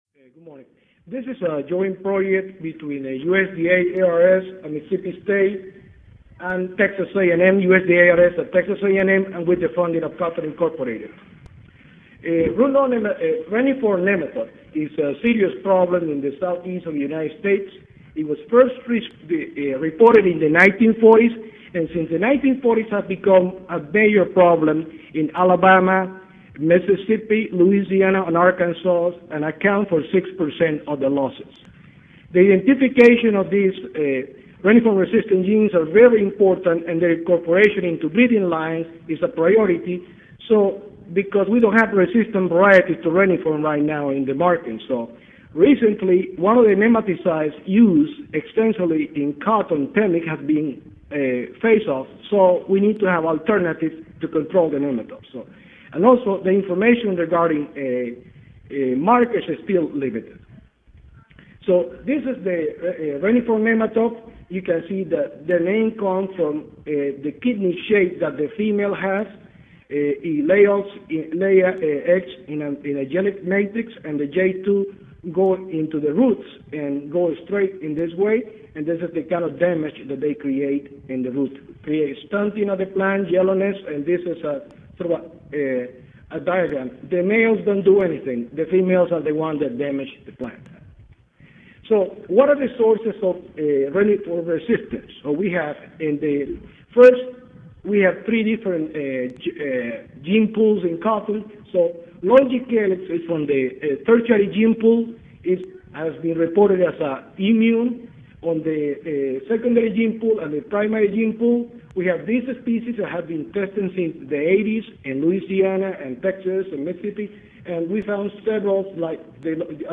C01 Crop Breeding & Genetics Session: Breeding and Genetics of Improved Pest Resistance (2010 Annual Meeting (Oct. 31 - Nov. 3, 2010))
Recorded presentation